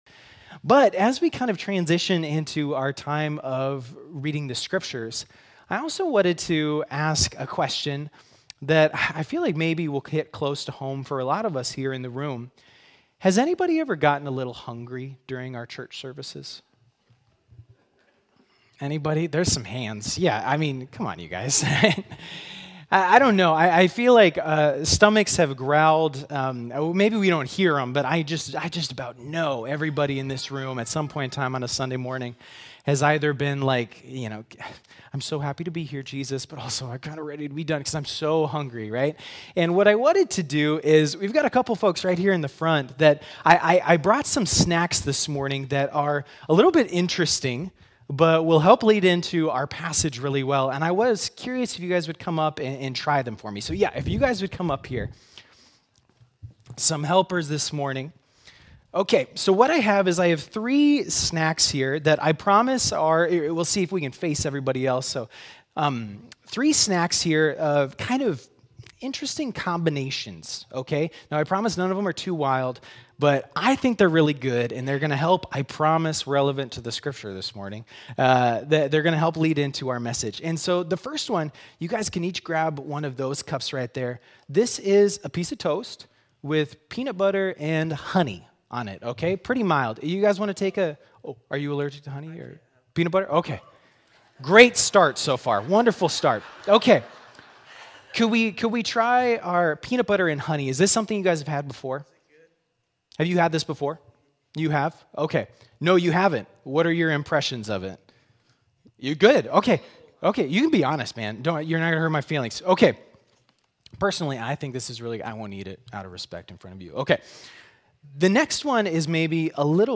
Sermons | Harrisburg United Methodist Church